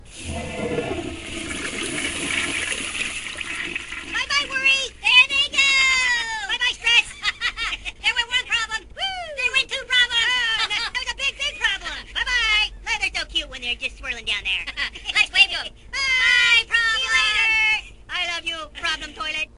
Flush the Stress! is a hoops&yoyo jumbo greeting card with sound made for friendship.
Card sound